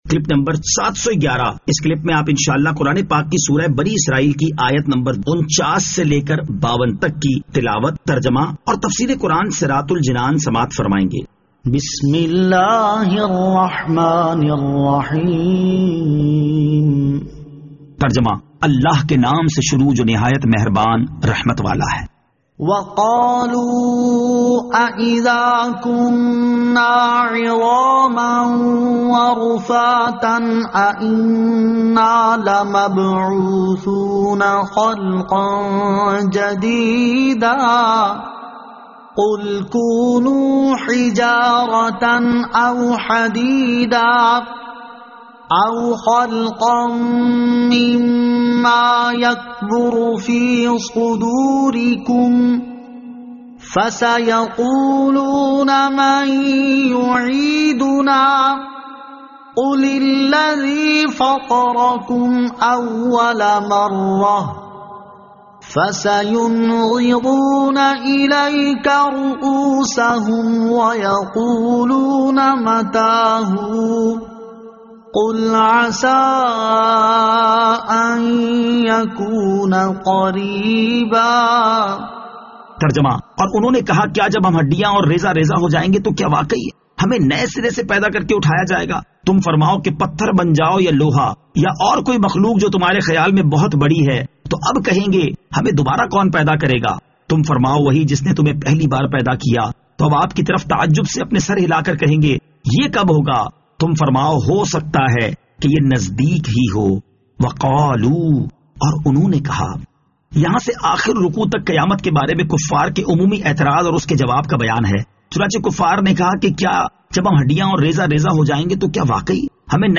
Surah Al-Isra Ayat 49 To 52 Tilawat , Tarjama , Tafseer